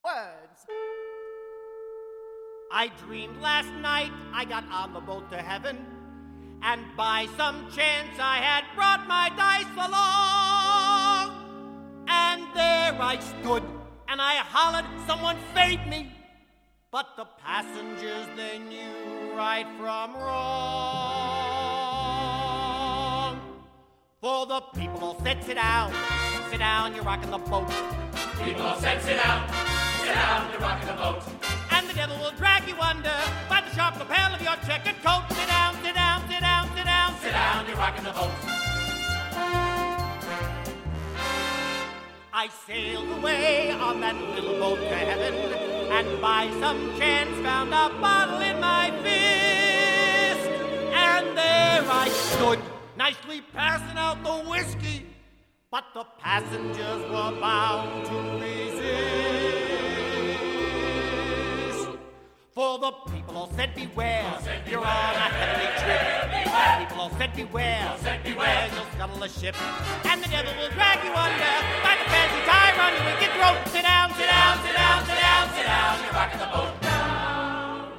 High Voices